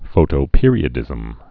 (fōtō-pîrē-ə-dĭzəm)